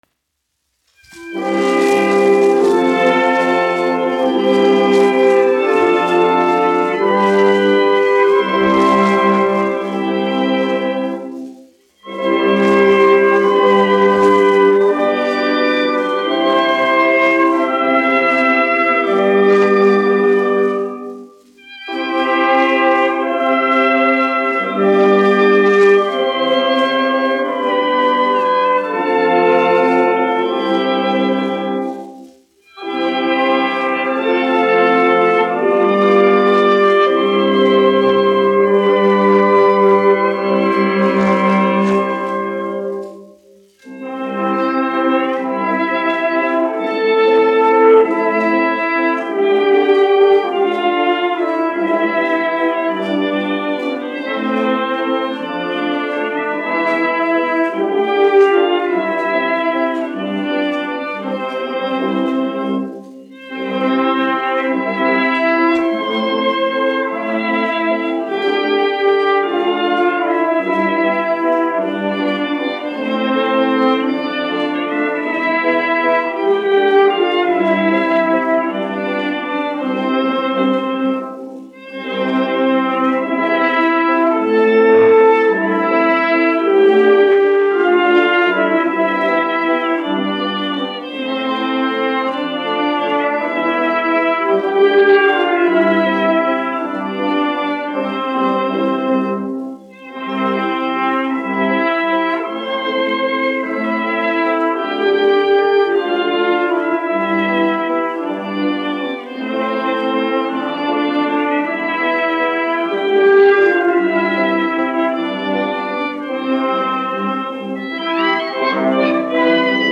1 skpl. : analogs, 78 apgr/min, mono ; 25 cm
Populārā instrumentālā mūzika
Latvijas vēsturiskie šellaka skaņuplašu ieraksti (Kolekcija)